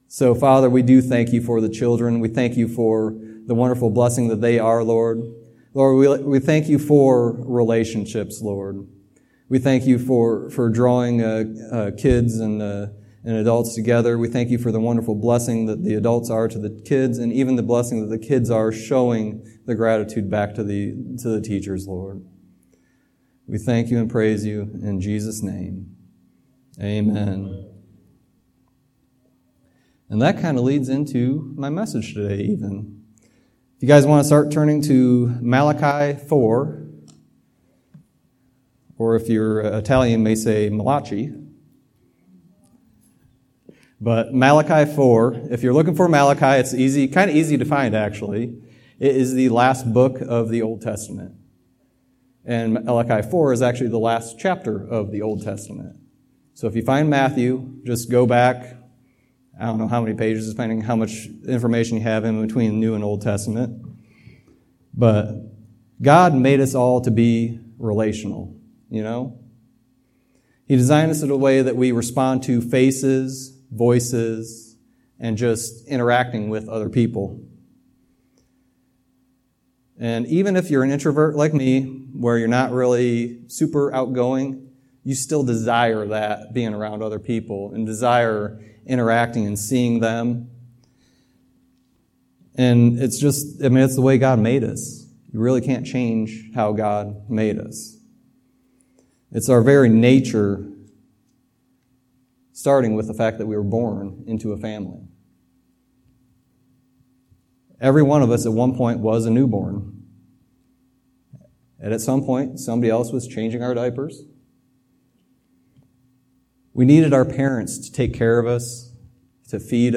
Sermon messages available online.
Malachi 4:5-6 Service Type: Sunday Teaching Its important to realize how vital it is to have alignment among the generations.